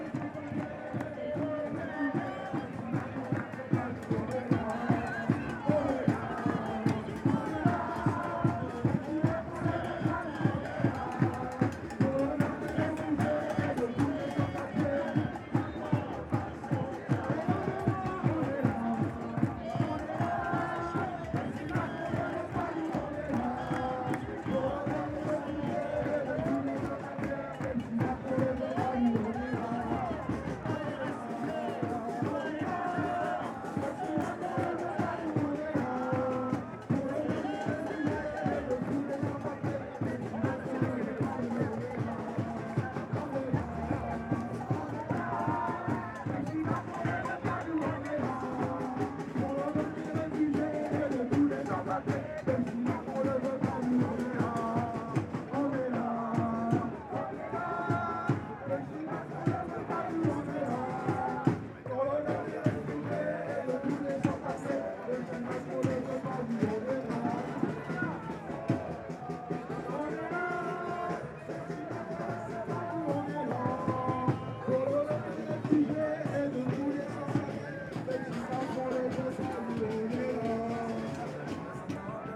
Demonstration in Paris.
UCS Category: Ambience / Protest (AMBPrtst)
Type: Soundscape
Channels: Stereo
Disposition: ORTF
Conditions: Outdoor
Realism: Realistic
Equipment: SoundDevices MixPre-3 + Neumann KM184